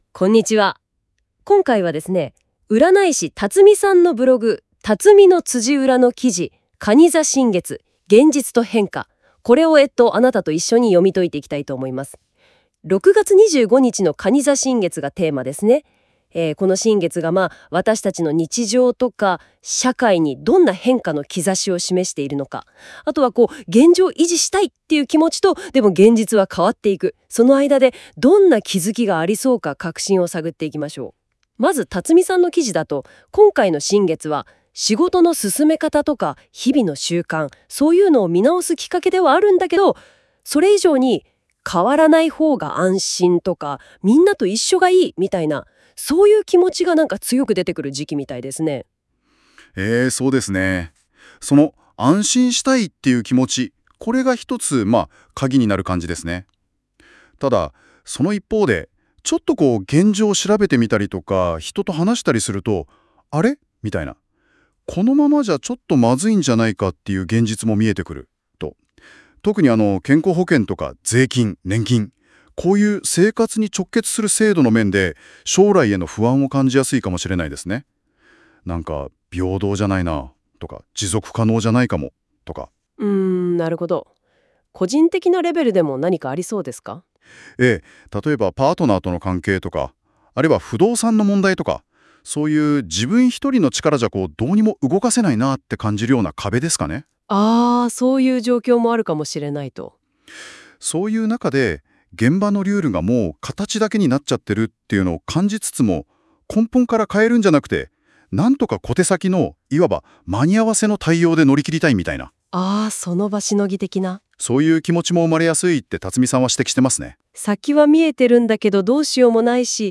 今回、実験的にnotebookLMで音声概要をしてみました。